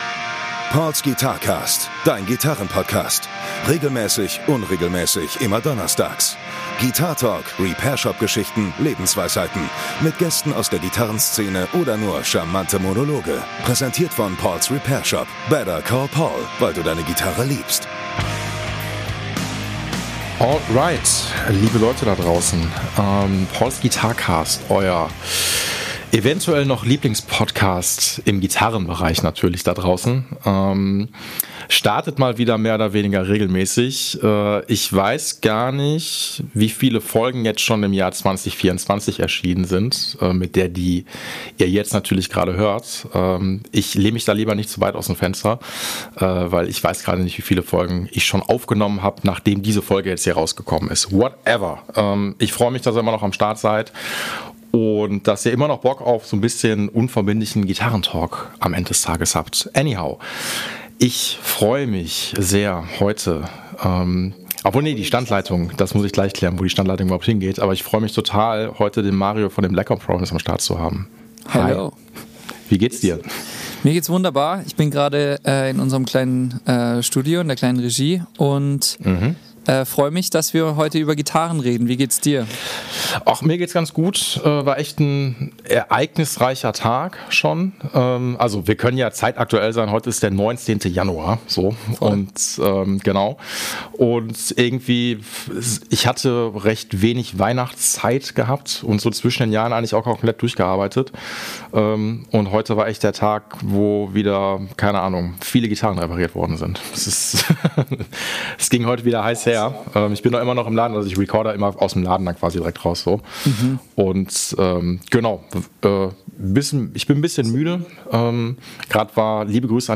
Störgeräusche inklusive! Wie kommt man als Band eigentlich wieder so richtig rein, wenn eine Tour ansteht?